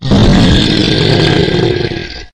growl-3.ogg